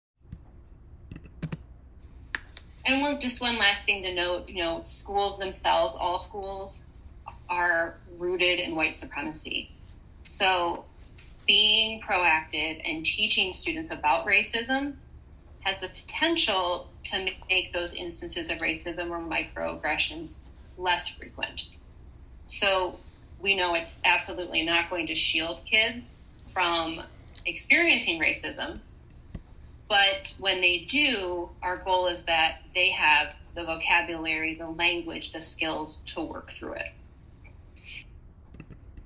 Parent University is a series of virtual learning programs the school holds with parents.